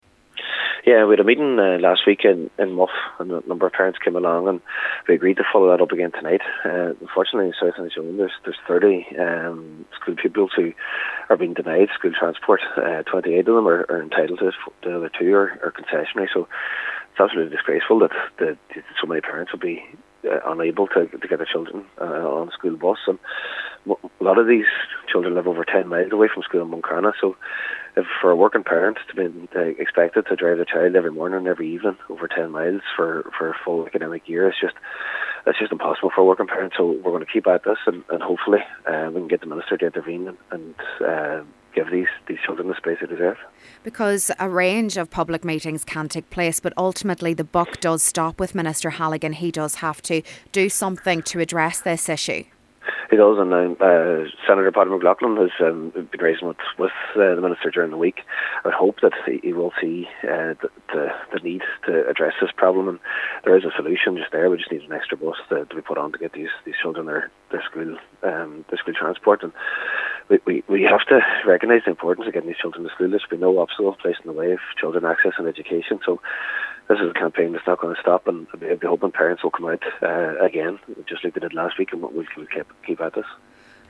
Cllr Jack Murray says it’s important to keep the pressure on and is encouraging everyone to attend: